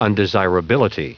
Prononciation du mot undesirability en anglais (fichier audio)
Prononciation du mot : undesirability
undesirability.wav